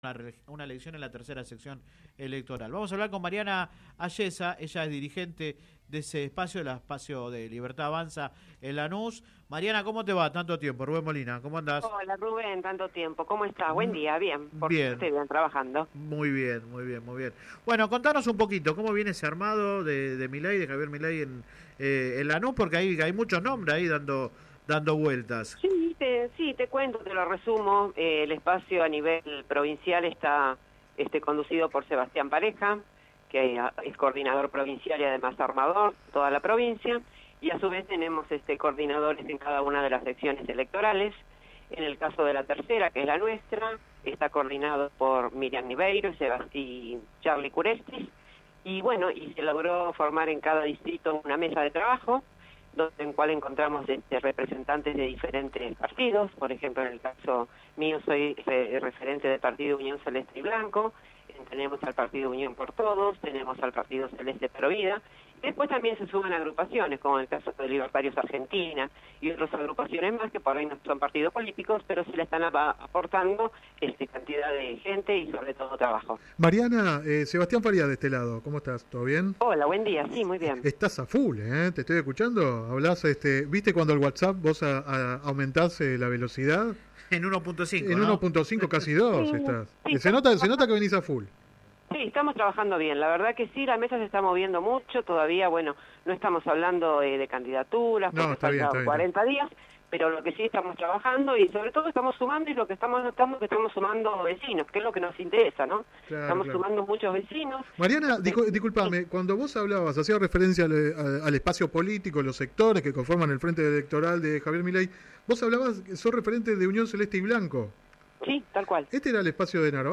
Adelantó su preferencia para que el dirigente Sebastián Pareja sea el candidato a gobernador por “Libertad Avanza”, aunque reconoció que aún no hay candidaturas confirmadas. La ex titular del Consejo Escolar lanusense, que habló en el programa radial Sin Retorno (lunes a viernes de 10 a 13 por GPS El Camino FM 90 .7 y AM 1260), no descartó ser parte de la lista de concejales.